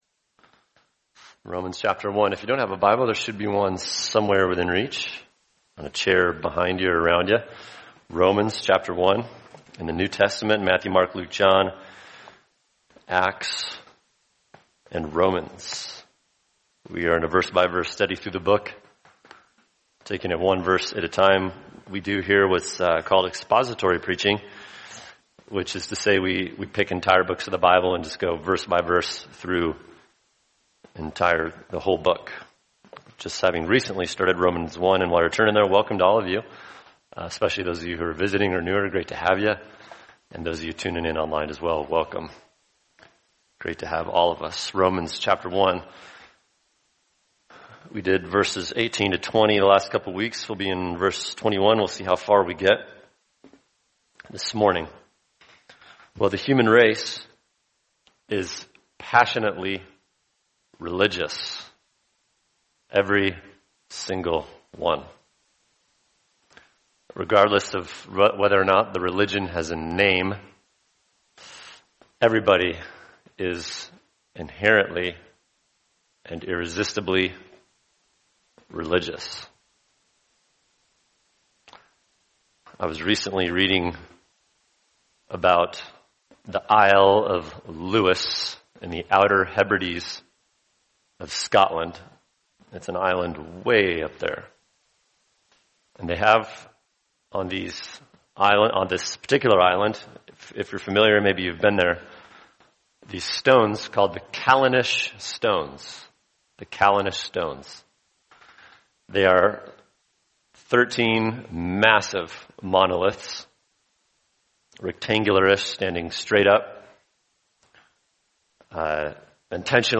[sermon] Romans 1:21-23 Reasons for God’s Wrath: Refusal to Worship | Cornerstone Church - Jackson Hole